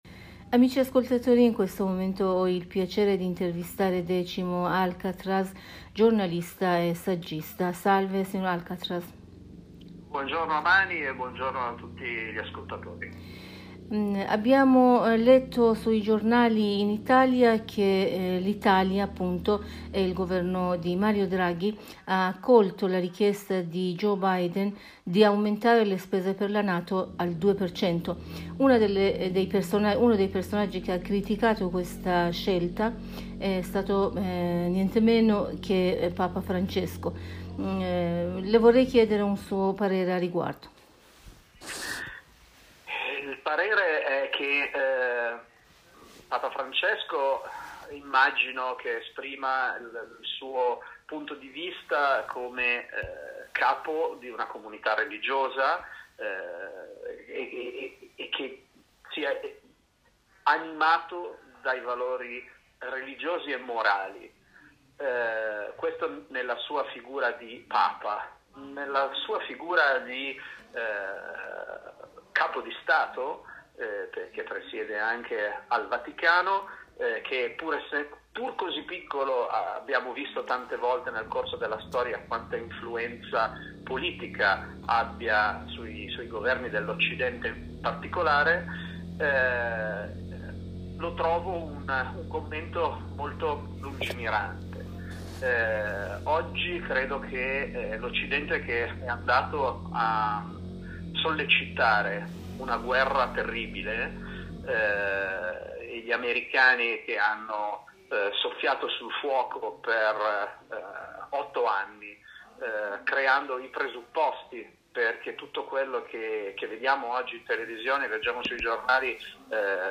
in un collegamento telefonico con la Radio Italia della Voce della Repubblica islamica dell'Iran (IRIB) con il sito http